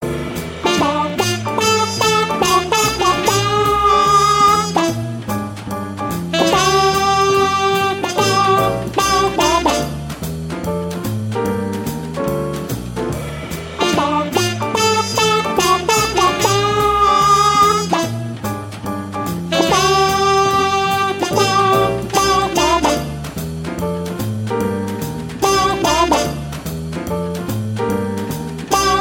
Kategorien Wecktöne